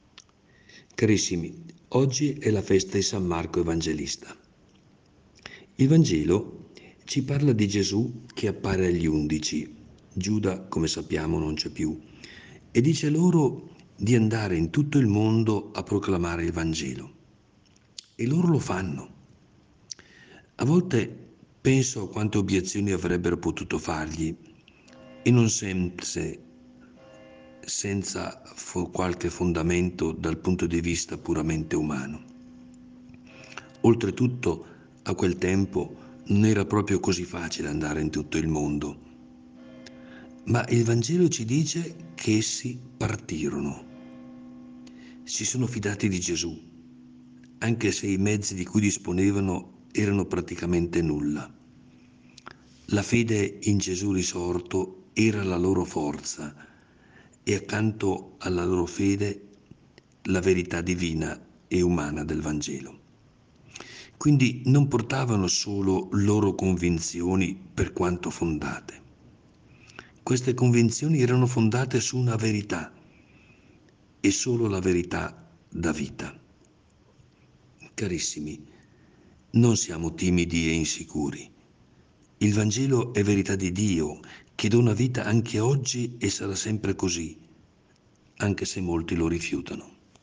PODCAST – Prosegue la rubrica podcast “In ascolto della Parola”, curata da Mons. Carlo Bresciani, vescovo della Diocesi di San Benedetto del Tronto – Ripatransone – Montalto, il quale ci accompagnerà con un contributo quotidiano.
Il Vescovo commenta la Parola di Dio per trarne ispirazione per la giornata.